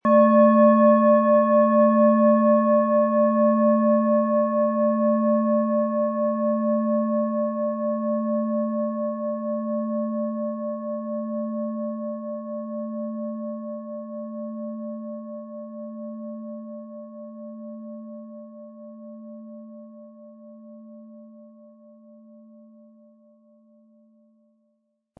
Planetenschale® Stabil fühlen und sein & Löse Dich von alten Strukturen mit Saturn & Uranus, Ø 17,5 cm, 700-800 Gramm inkl. Klöppel
• Tiefster Ton: Uranus
Um den Originalton der Schale anzuhören, gehen Sie bitte zu unserer Klangaufnahme unter dem Produktbild.
Der richtige Schlegel ist umsonst dabei, er lässt die Klangschale voll und angenehm erklingen.
PlanetentöneSaturn & Uranus
HerstellungIn Handarbeit getrieben
MaterialBronze